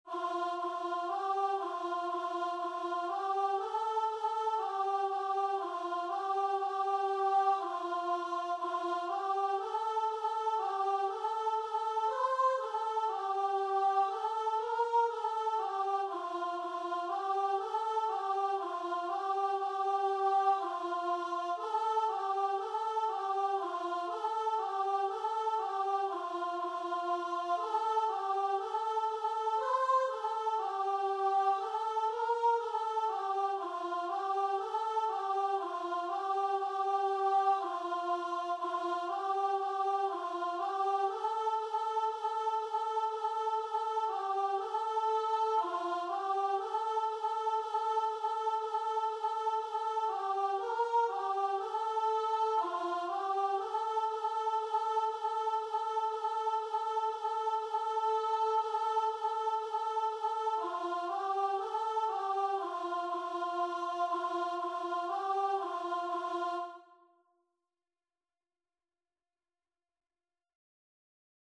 Free Sheet music for Voice
C major (Sounding Pitch) (View more C major Music for Voice )
4/4 (View more 4/4 Music)
F5-C6
Voice  (View more Easy Voice Music)
Christian (View more Christian Voice Music)
requiem_aeternam_VO.mp3